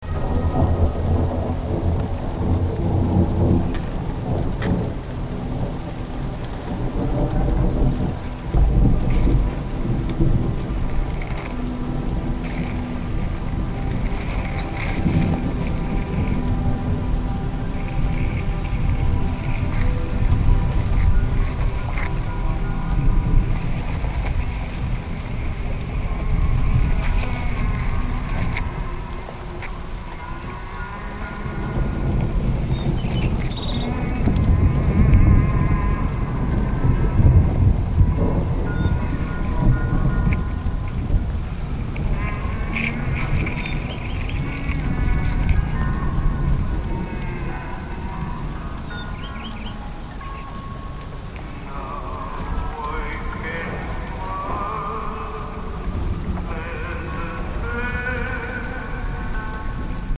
Techno Ambient